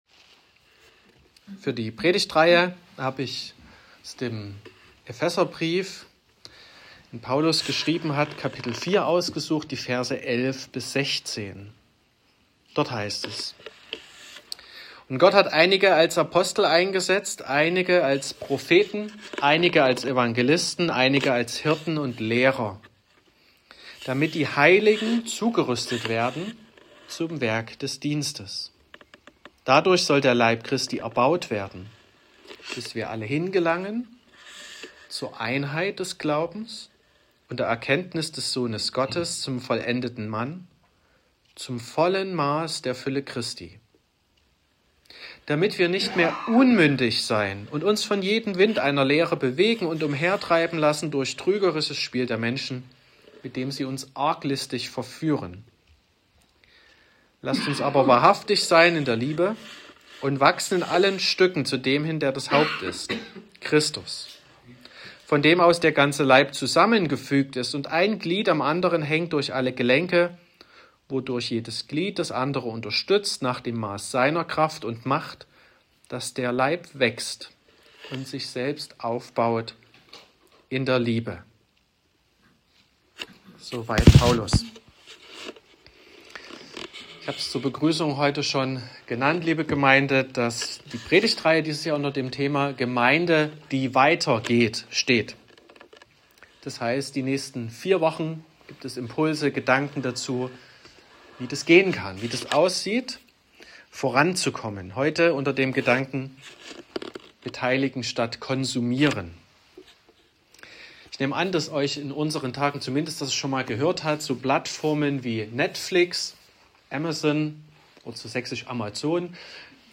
18.01.2026 – Gottesdienst
Predigt und Aufzeichnungen